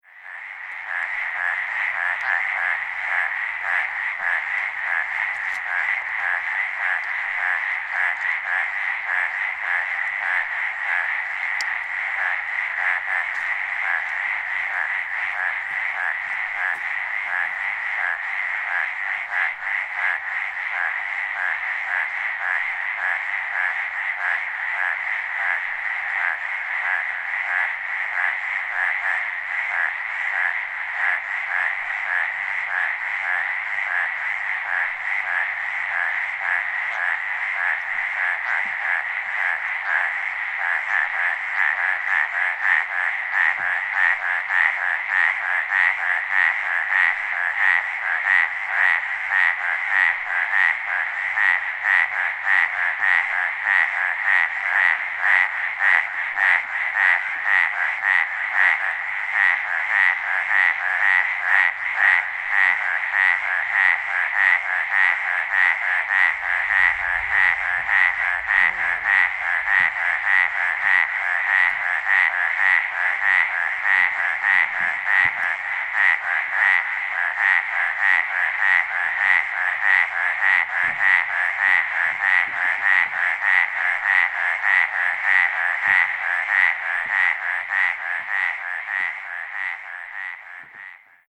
Advertisement Calls
The following sounds were recorded at night in the mountains of Coconino County, Arizona at the
Sound  This is a 90 second recording of the advertisement calls of a large group of Arizona Treefrogs calling at night in Coconino County, Arizona.